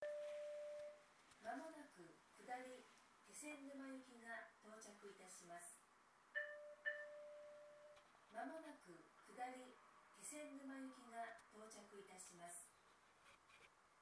この駅では接近放送が設置されています。
接近放送普通　気仙沼行き接近放送です。